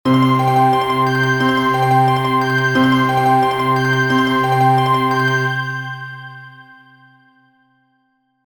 急行
接近メロディー(急行).mp3